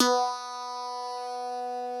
genesis_bass_047.wav